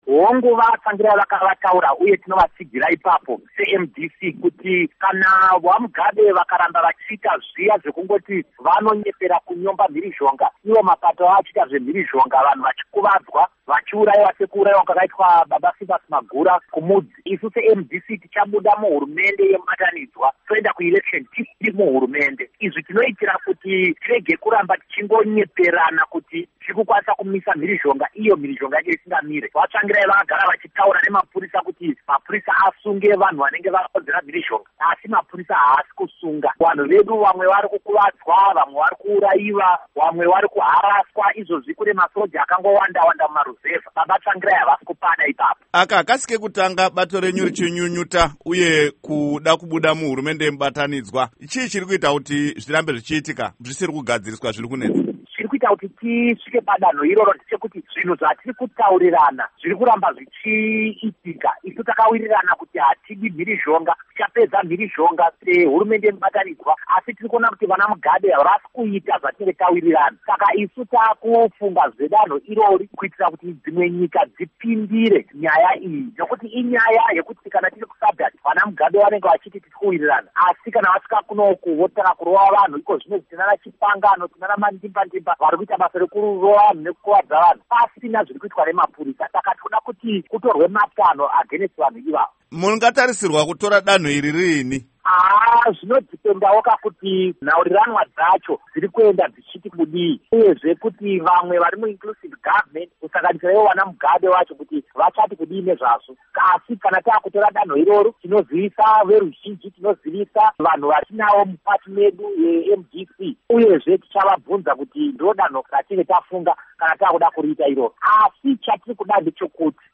Embed share Hurukuro naVaDouglas Mwonzora by VOA Embed share The code has been copied to your clipboard.